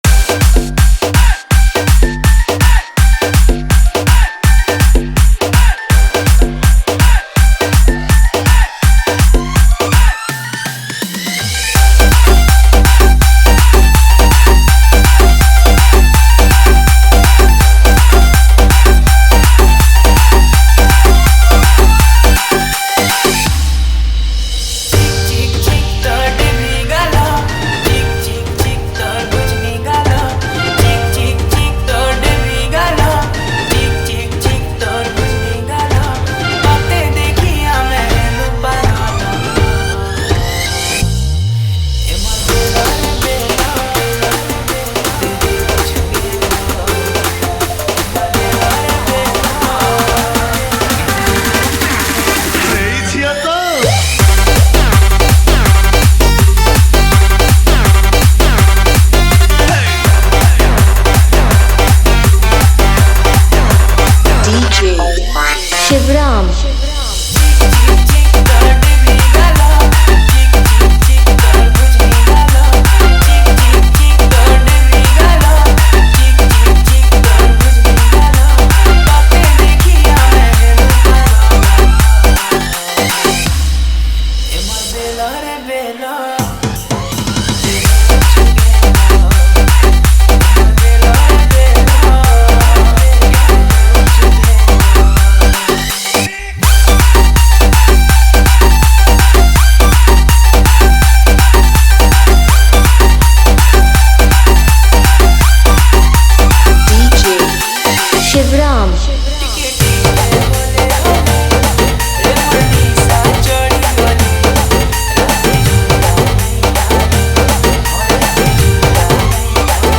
Category:  New Sambalpuri Dj Song 2023